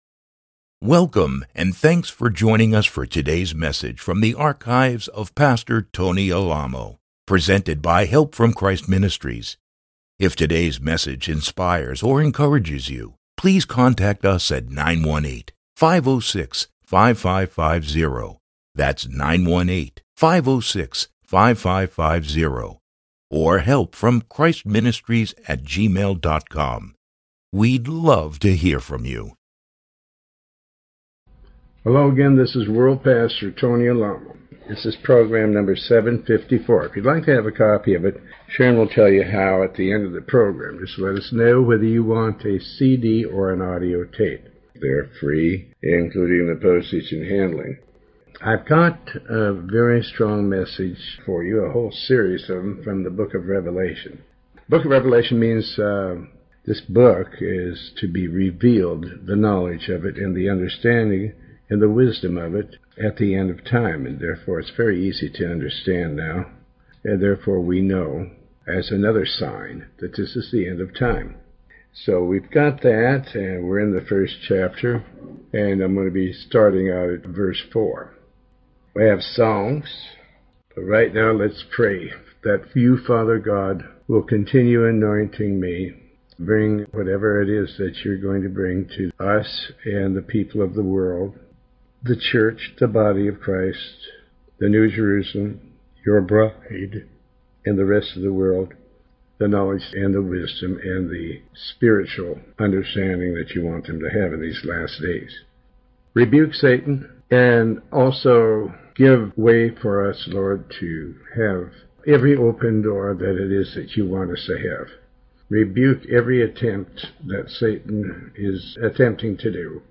Sermon 754A